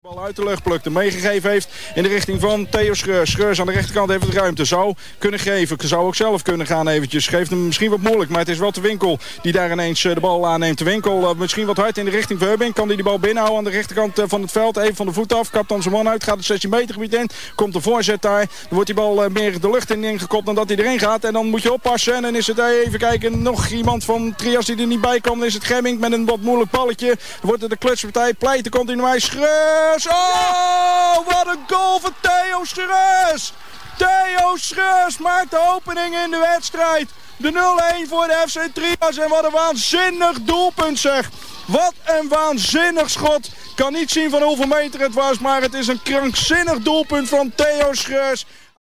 geluidsfragment van het inmiddels legendarische doelpunt
in de nacompetitiewedstrijd tussen MEC en FC Trias